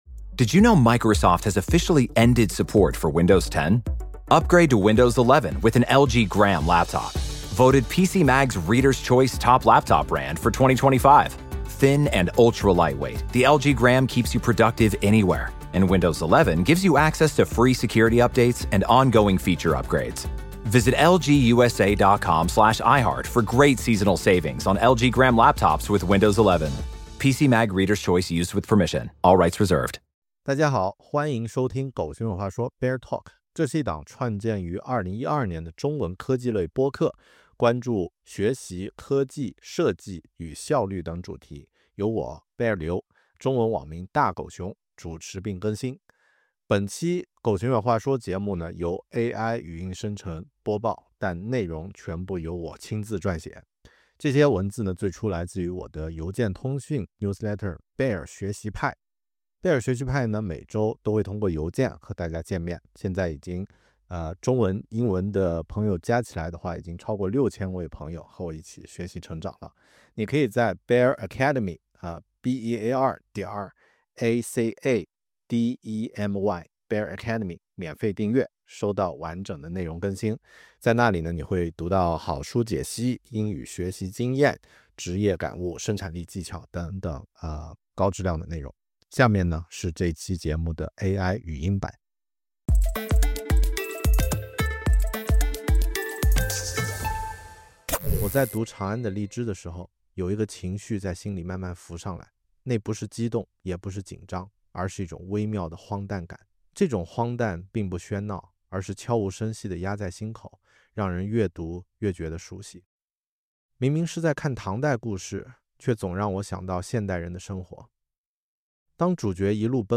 1 528 / 给自我怀疑者的心态校准 /A Mindset Reset for Creators [AI朗读] 17:34
1 528 / 给自我怀疑者的心态校准 /A Mindset Reset for Creators [AI朗读] 17:34 Play Pause 10d ago 17:34 Play Pause Later Afspelen Later Afspelen Lijsten Vind ik leuk Leuk 17:34 如果你最近也在自我怀疑、被评论刺到，这期会帮你搭一个属于自己的「反馈图书馆」，慢慢把心收回来。 在这一期节目中，你听到的声音来自 AI，但内容全部由我亲自撰写。